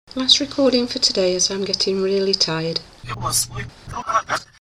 Sony B300 and reversed bulgarian background